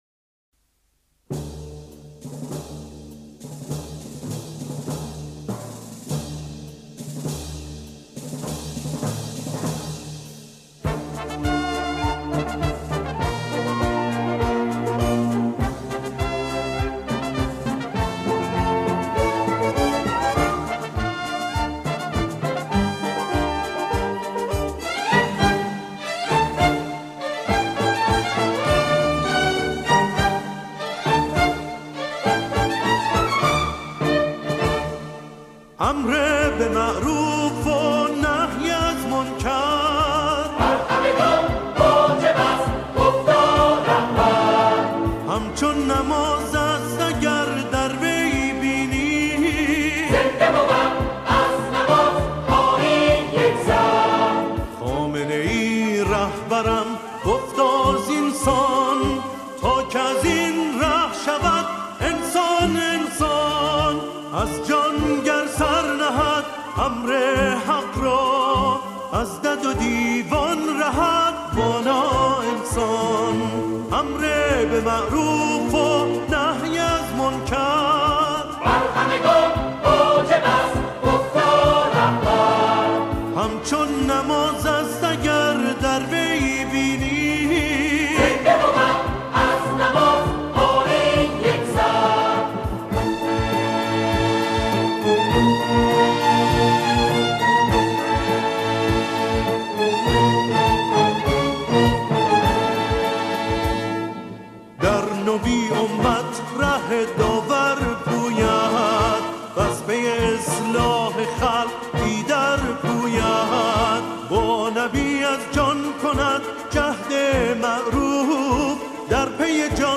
در این قطعه، شعری با موضوع سبک زندگی همخوانی می‌شود.